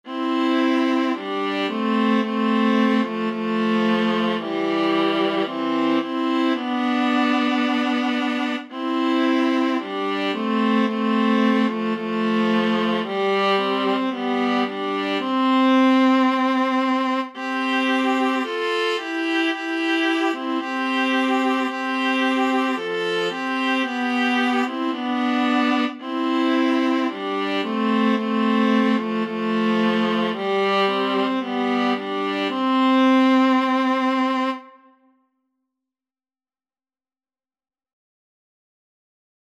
Free Sheet music for Viola Duet
C major (Sounding Pitch) (View more C major Music for Viola Duet )
4/4 (View more 4/4 Music)
Viola Duet  (View more Easy Viola Duet Music)
Classical (View more Classical Viola Duet Music)